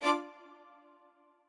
strings5_38.ogg